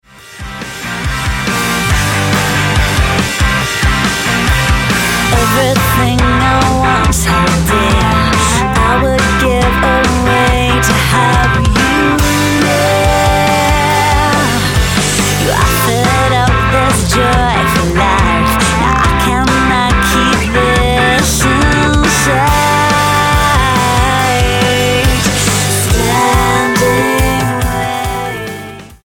Australian pop rockers
Style: Pop